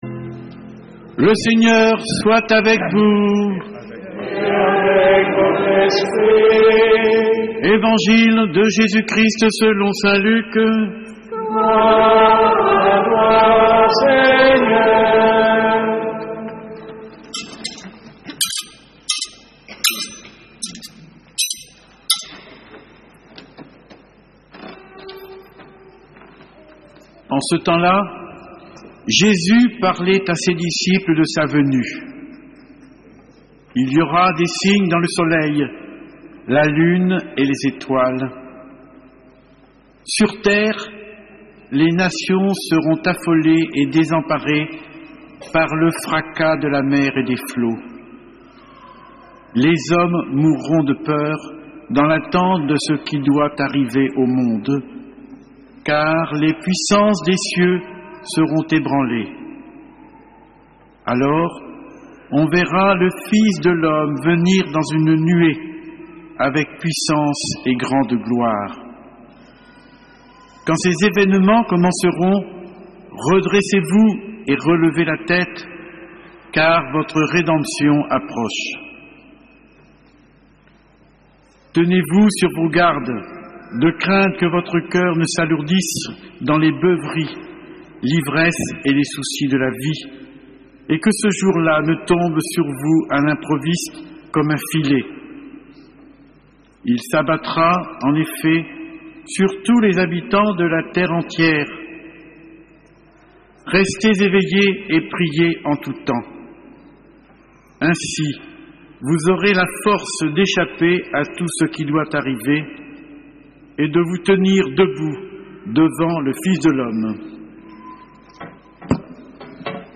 homelie-3.mp3